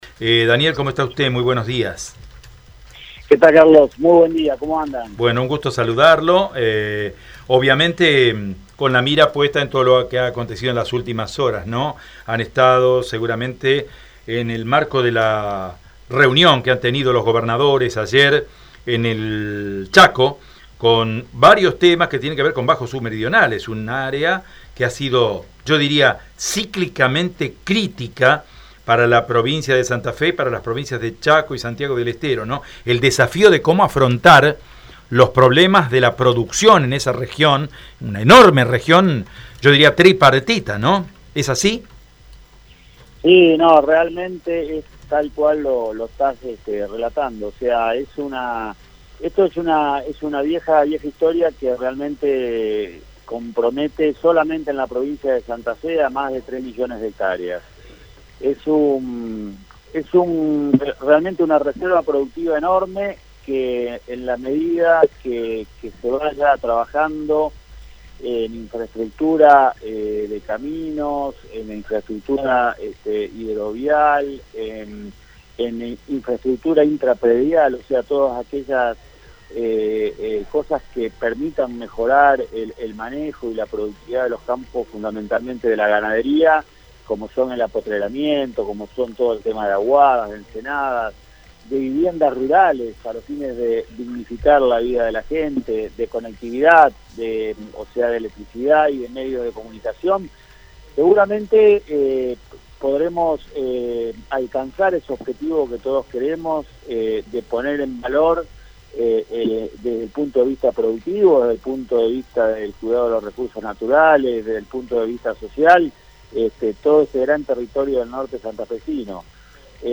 Daniel Costamagna en Radio EME: